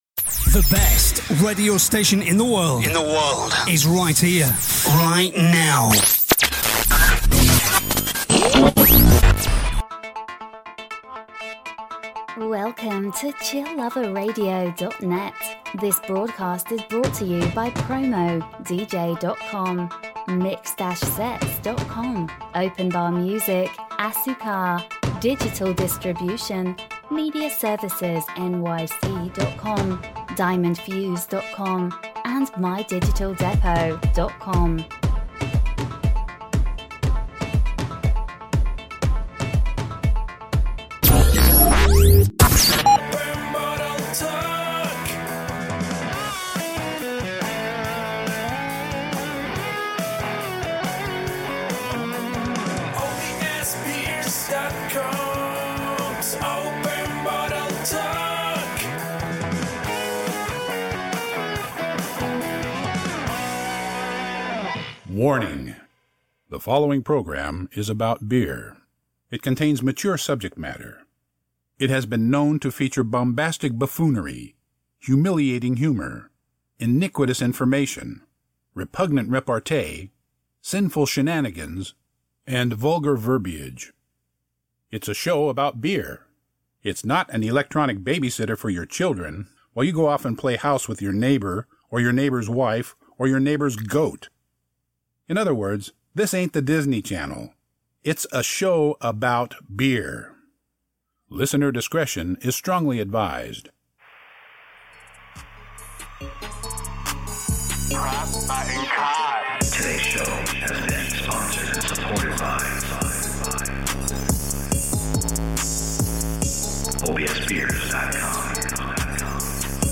Styles: Beer Talk, Beer News, Beer, Craft Beers, Talk Show, Comedy